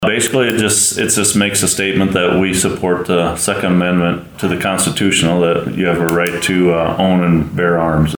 Humboldt, IA – At Monday’s Humboldt County Board of Supervisors meeting the board approved a resolution declaring Humboldt County to be a second amendment Sanctuary County. Humboldt County Sheriff Dean Kruger has more.
Kruger-on-2nd-amendment-1.mp3